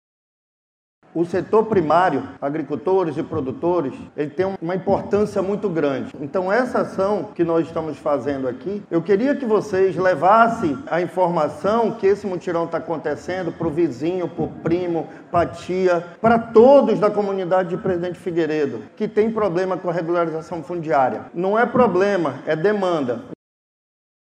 O diretor-presidente do Ipaam, Gustavo Picanço, ressalta a importância da união entre os órgãos envolvidos e evidenciou o papel fundamental dos produtores rurais.